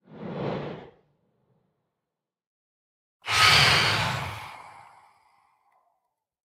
Minecraft Version Minecraft Version 1.21.5 Latest Release | Latest Snapshot 1.21.5 / assets / minecraft / sounds / mob / phantom / swoop4.ogg Compare With Compare With Latest Release | Latest Snapshot
swoop4.ogg